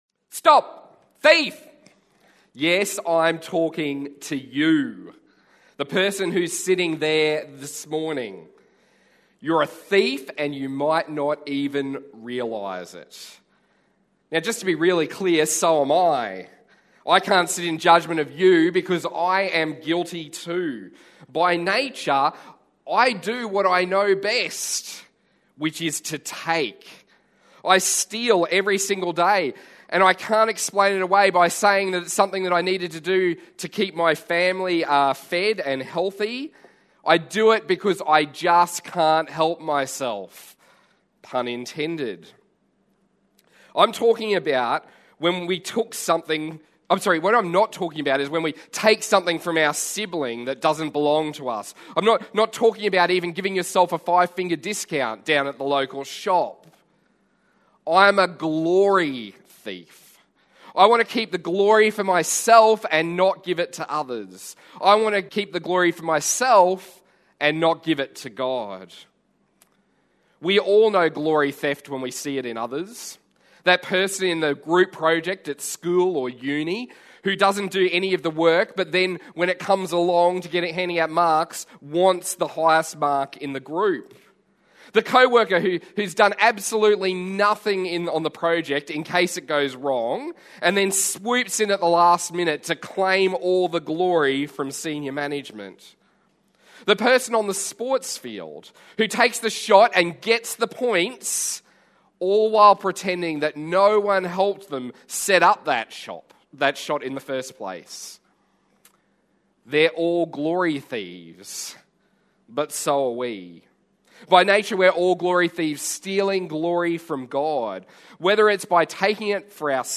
Sing Like Jesus Passage: Psalm 115 Service Type: Morning Service « Self-Control